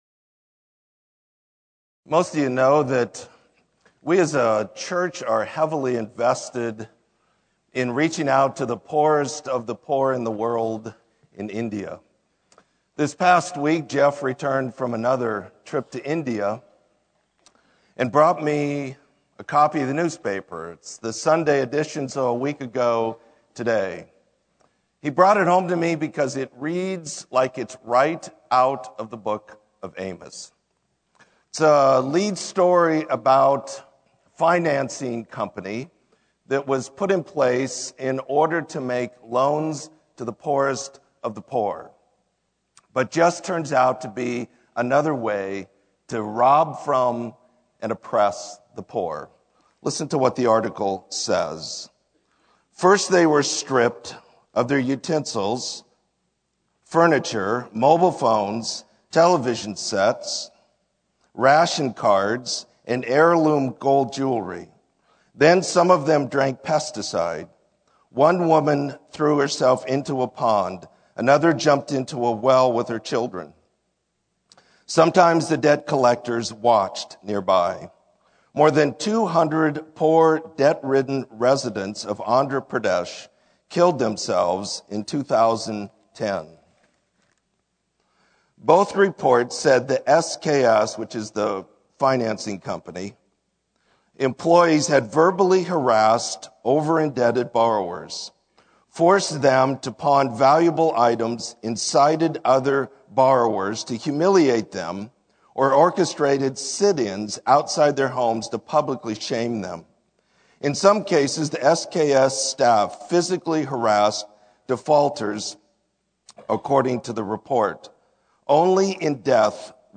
Sermon: Restoration